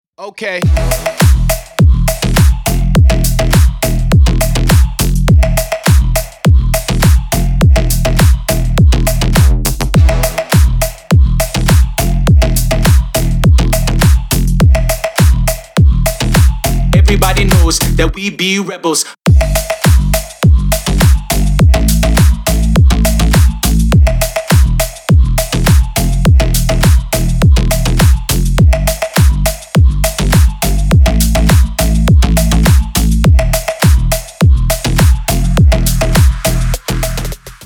Бодрая новинка на телефон